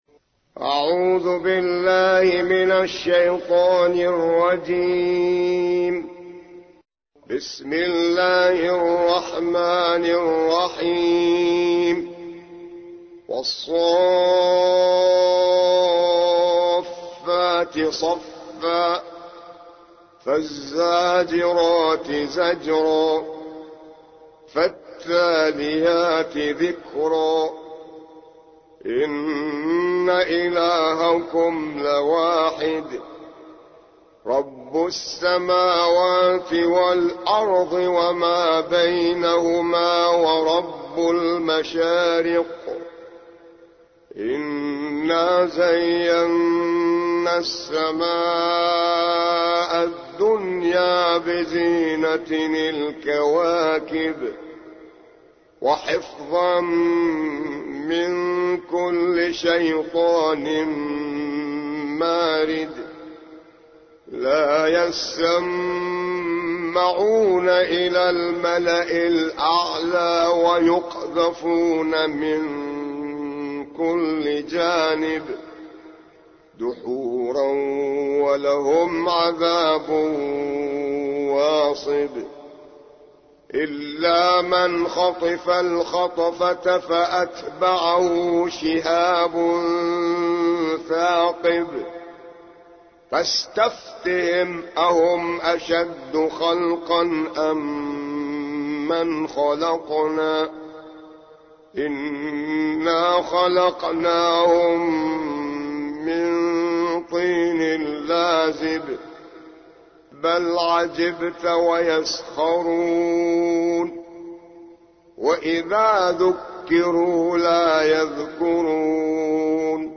موقع يا حسين : القرآن الكريم 37.